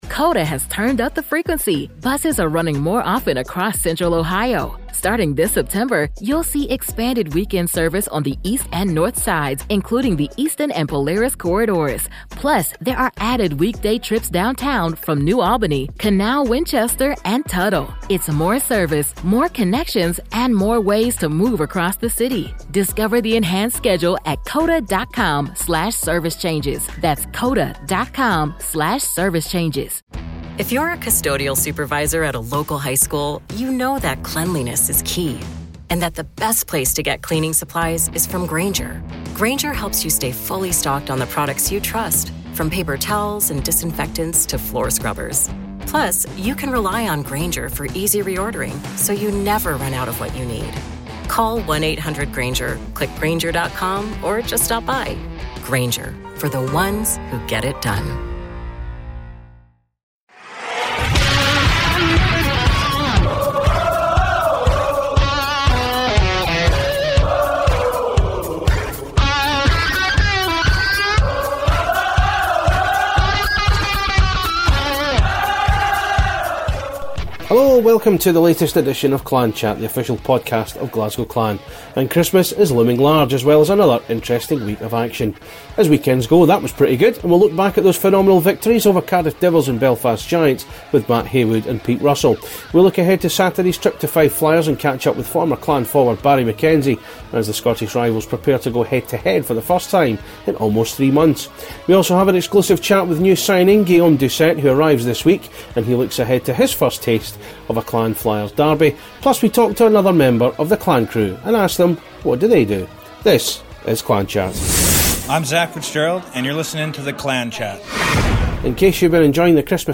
Clan Chat brings an exclusive interview